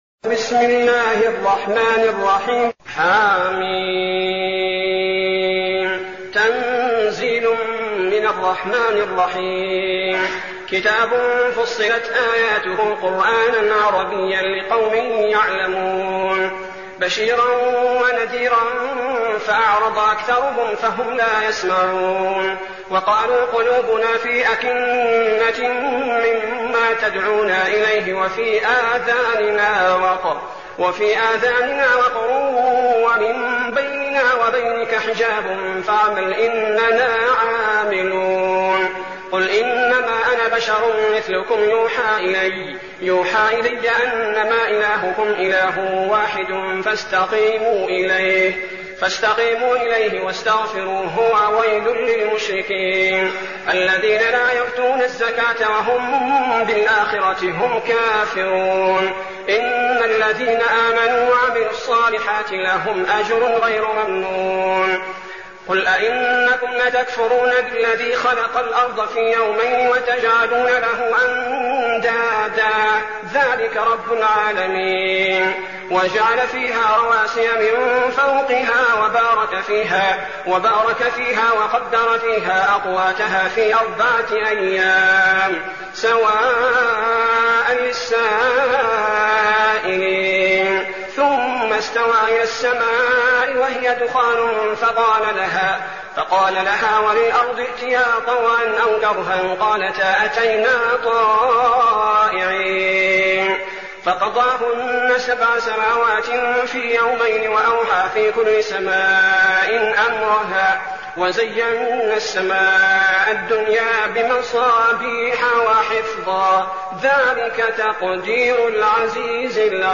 المكان: المسجد النبوي الشيخ: فضيلة الشيخ عبدالباري الثبيتي فضيلة الشيخ عبدالباري الثبيتي فصلت